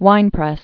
(wīnprĕs)